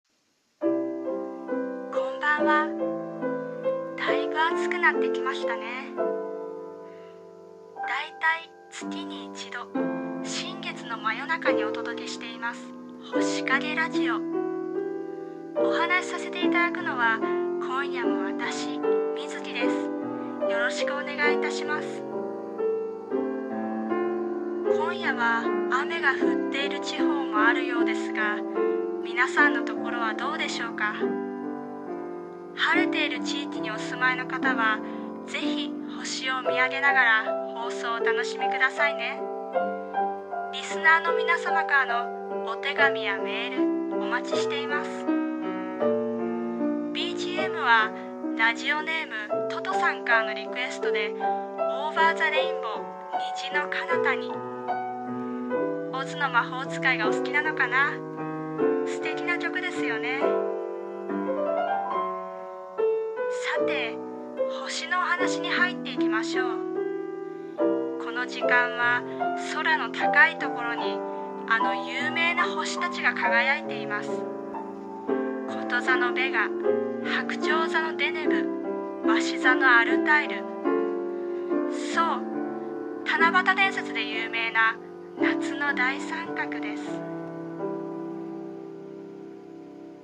さんの投稿した曲一覧 を表示 朗読台本「星影ラジオ 夏」